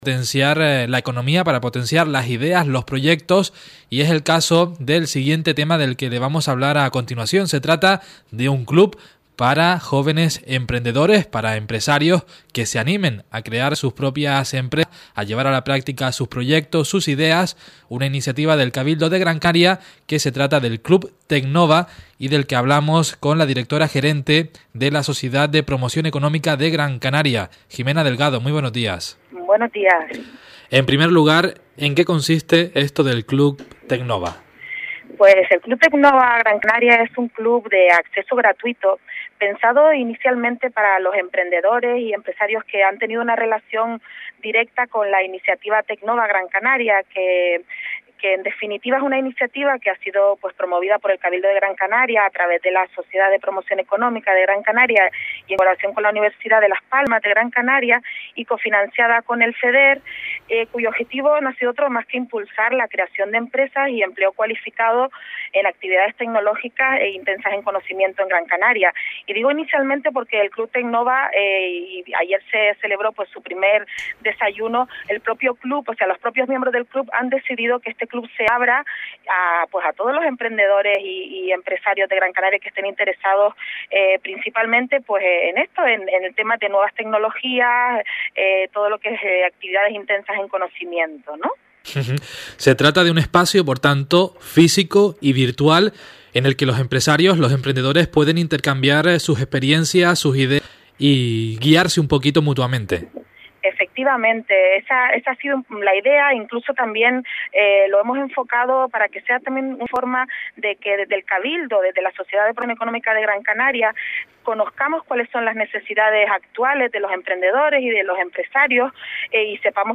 Entrevista Presentación Club Tecnova